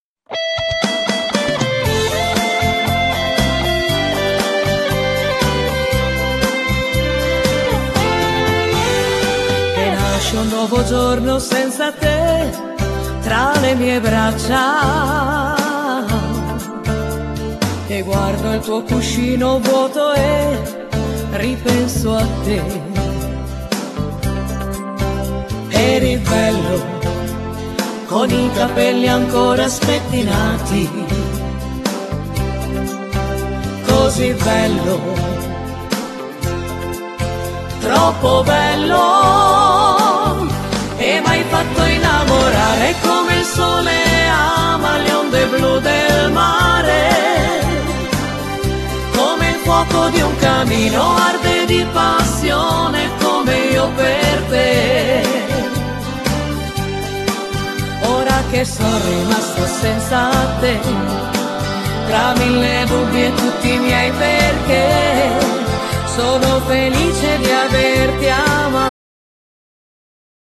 Genere : Pop Folk